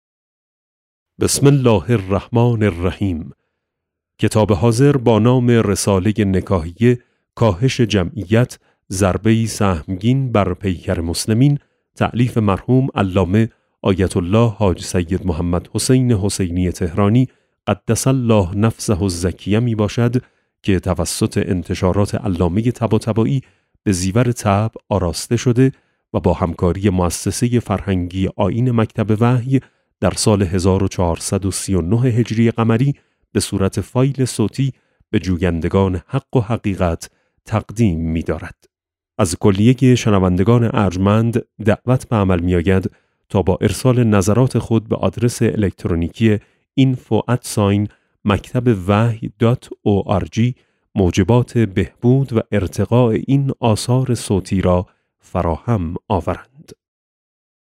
کتاب صوتی رساله نکاحیه - جلسه1